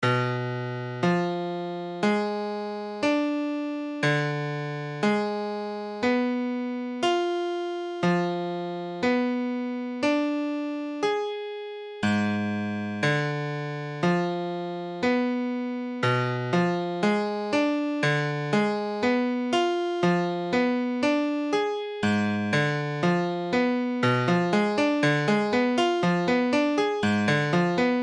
Tablature Cbdim7.abcCbdim7 : accord de Do bémol septième diminuée
Mesure : 4/4
Tempo : 1/4=60
Forme fondamentale : tonique quinte diminuée sixte tierce mineure
Cbdim7.mp3